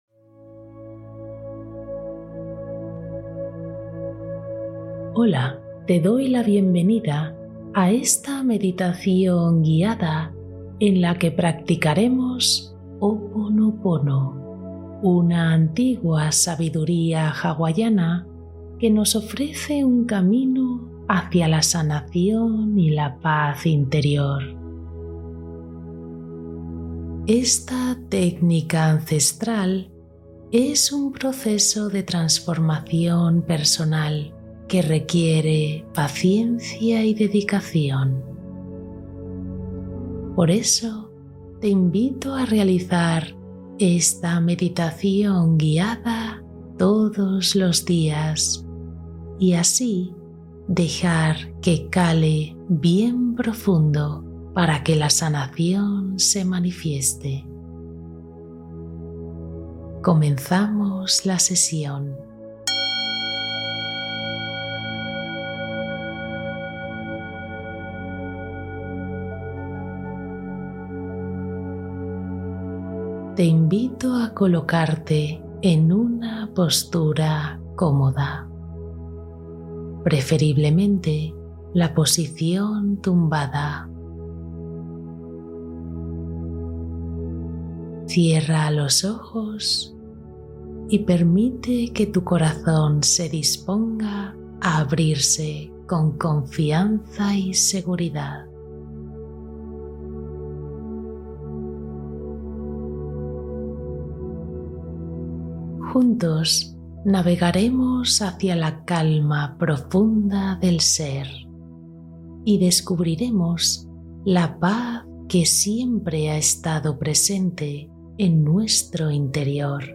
Sanación nocturna con Ho’oponopono: meditación para dormir profundamente